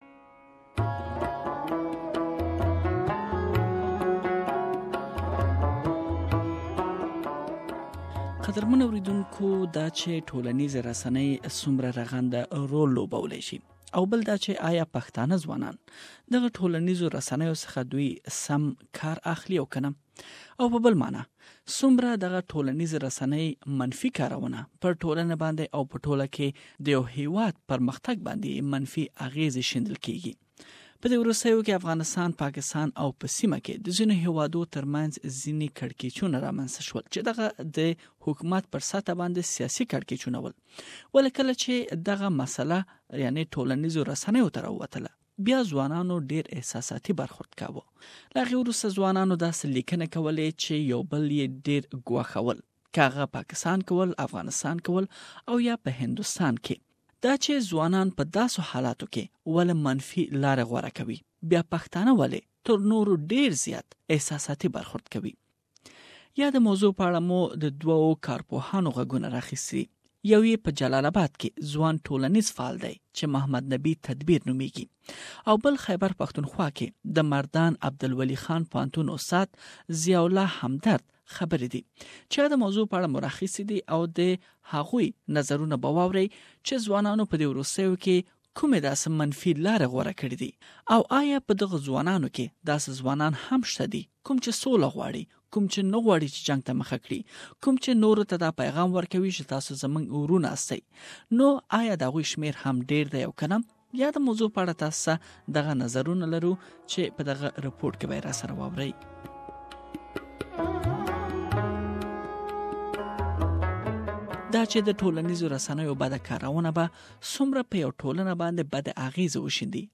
Some experts say that Pashtun youth often post negative and emotional reactions to news developments on social media, before knowing the full story. Two experts one from Afghanistan and one from Pakistan who closely monitor youth behavior spoke to Mashaal Radio from which we have prepared a report and you can listen to it here.